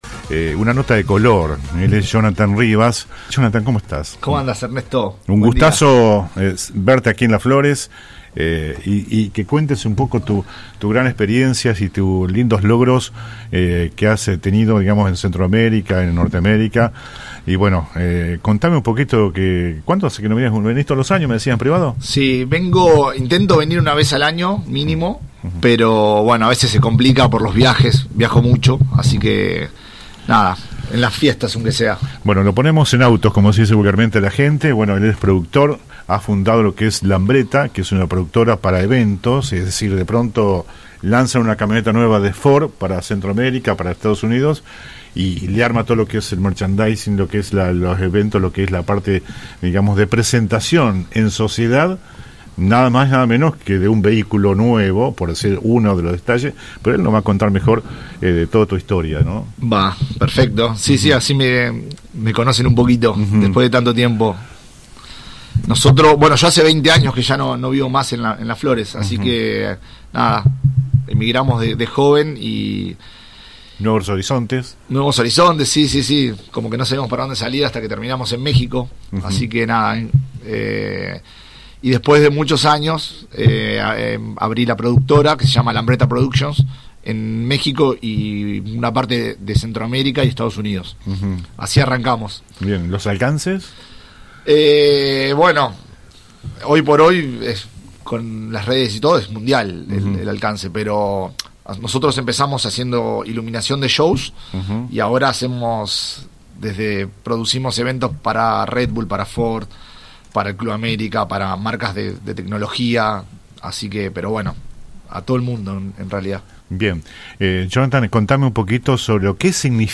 Hoy lo invitamos al piso de la radio para que nos cuente sus historias.